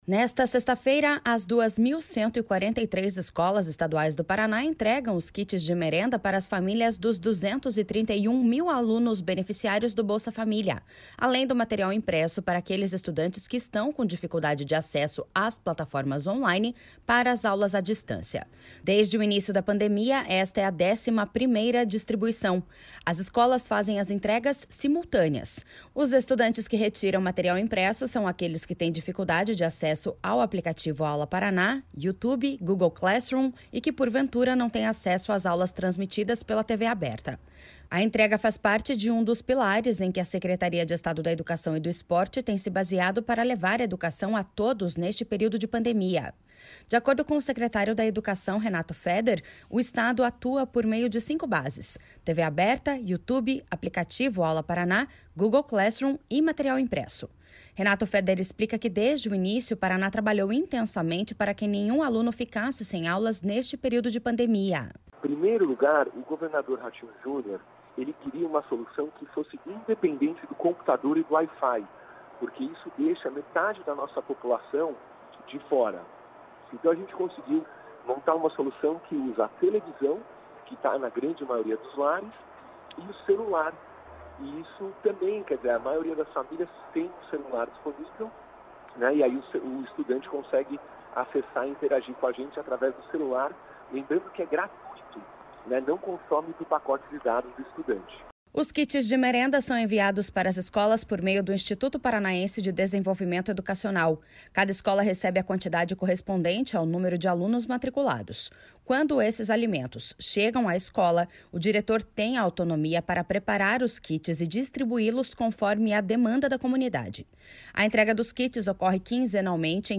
Renato Feder explica que desde o início o Paraná trabalhou intensamente para que nenhum aluno ficasse sem aulas neste período de pandemia.// SONORA RENATO FEDER.//